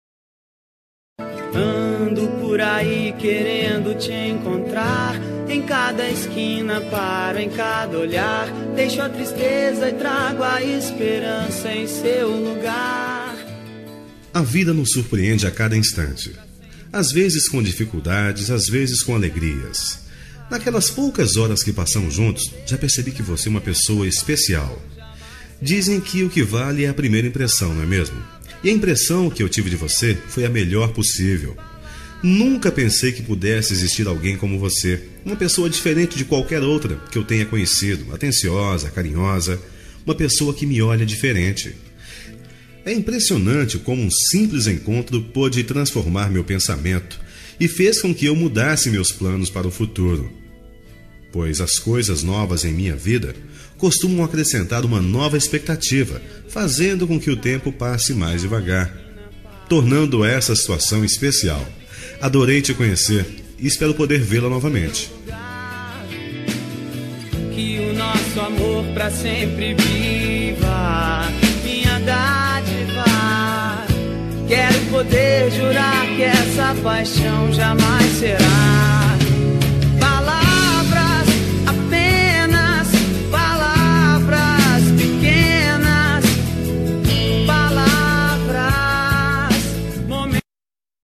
Telemensagem Momentos Especiais – Voz Masculina – Cód: 4109 – Adorei te Conhecer
4109-adorei-te-comnhecer-masc.m4a